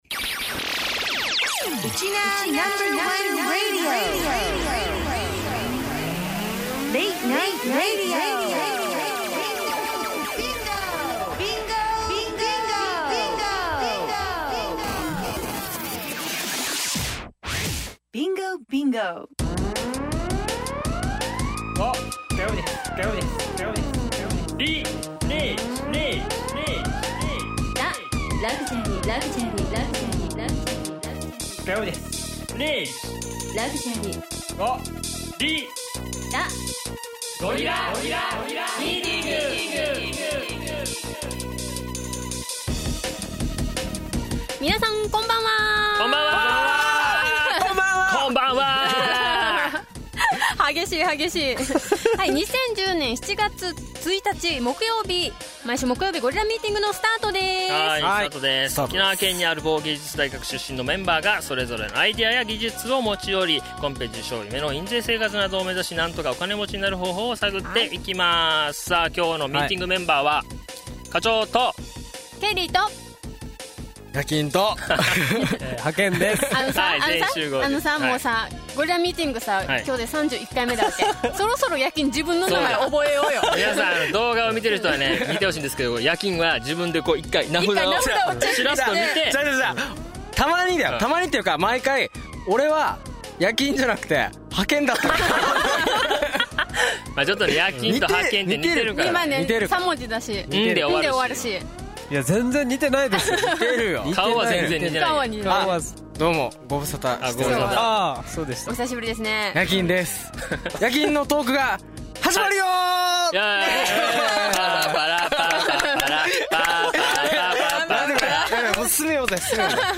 その模様をUST／ネットラジオで公開。